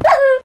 Minecraft / mob / wolf / hurt3.ogg
hurt3.ogg